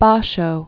(bäshō, bä-shô), Matsuo 1644-1694.